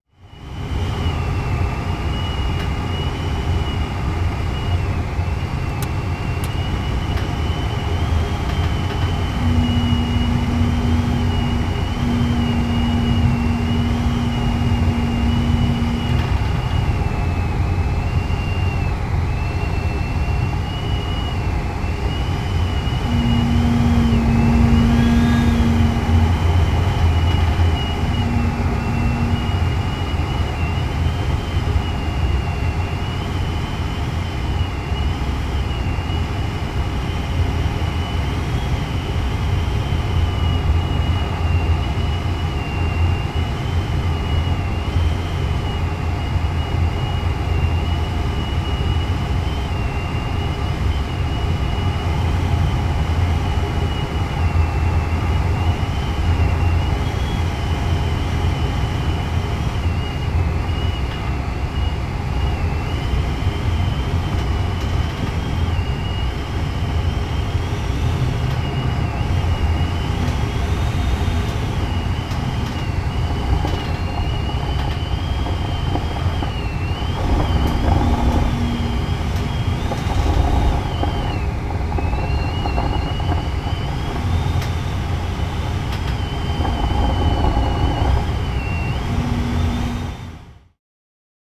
transport
Glider Cockpit Inside Noise